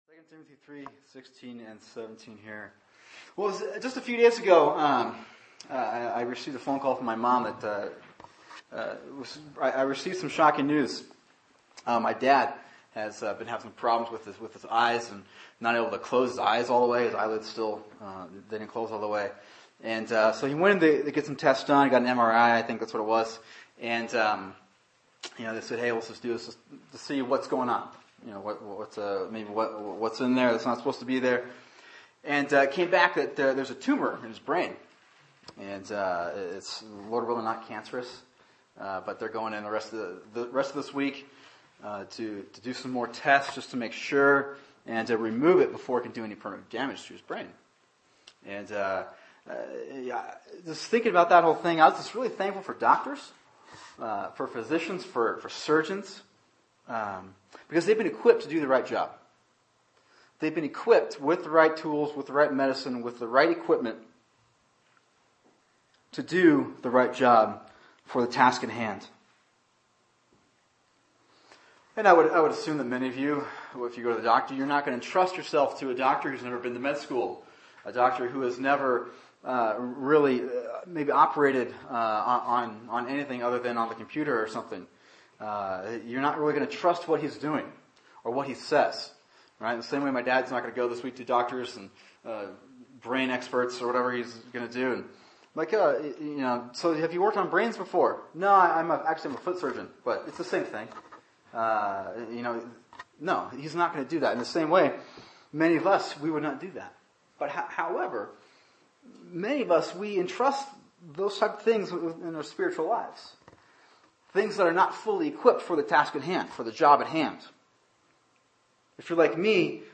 [sermon] 2 Timothy 3:16-17 “The Word and The Man” | Cornerstone Church - Jackson Hole